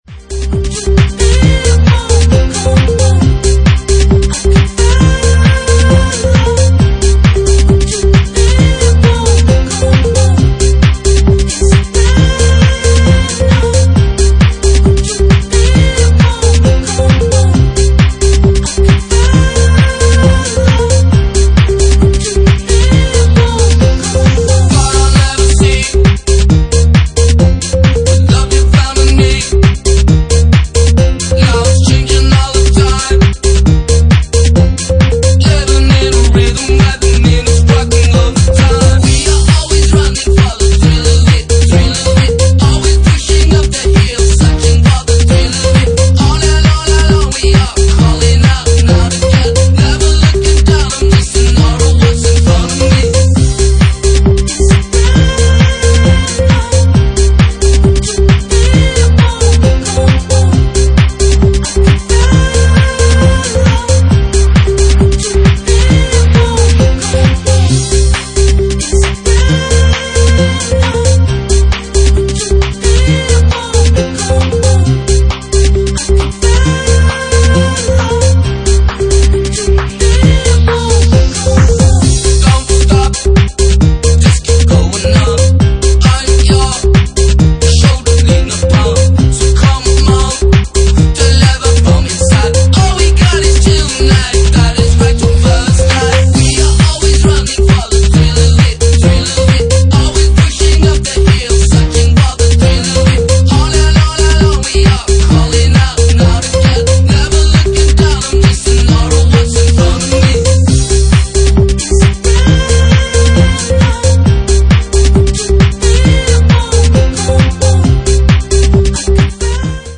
Genre:Bassline House
Bassline House at 67 bpm